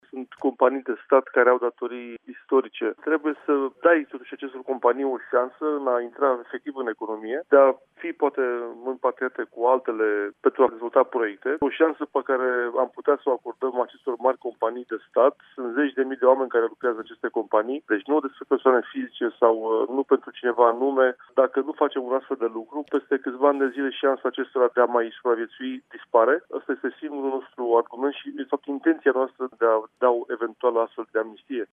Guvernul lucrează la un proiect privind amnistia fiscală pe care intenţionează să-l finalizeze şi să-l prezinte public în toamnă. Ministrul Finanţelor, Eugen Teodorovici, a declarat la Apelul Matinal de la Radio România Actualităţi, că este în pregătire un mecanism care să vină în sprijinul companiilor de stat cu datorii mari la buget, pentru a stimula economia: